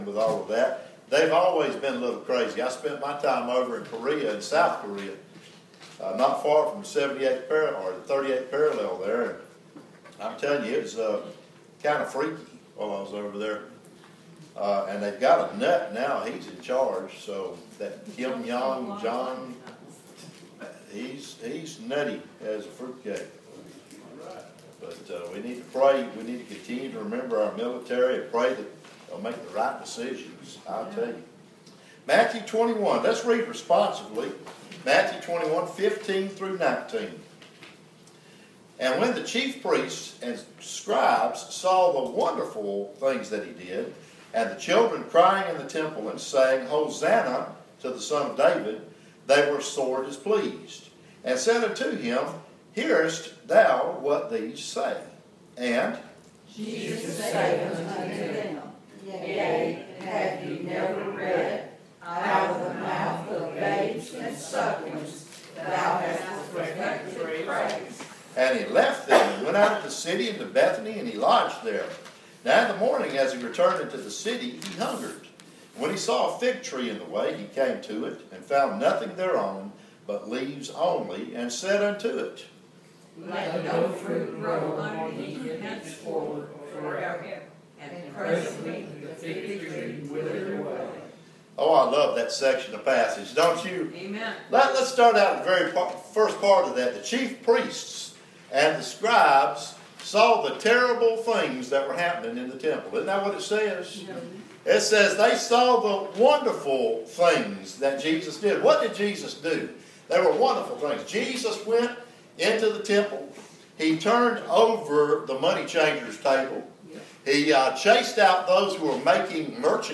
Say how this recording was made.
Preached at Riverview Baptist July 5, 2017.